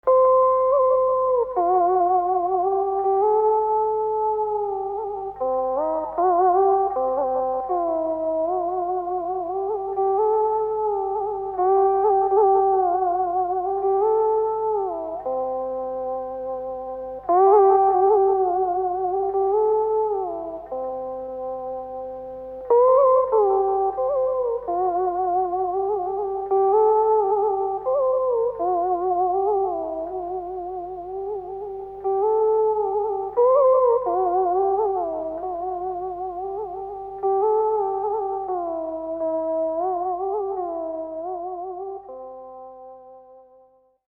The Monochord Dan Bau
The single chord is getting plucked with a plectrum ,while the same hand prdouces the overtone. The other hand modulates the tone by turning the swelling paddle at the resonating conus.
It's winig smooth voice is rich of formants and pleases every listener.
dan-bao.MP3